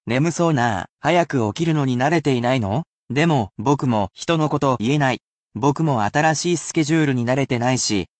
I can only read it at one speed, so there is no need to repeat after me, but it can still assist you in picking out vocabulary within natural speeds of speech.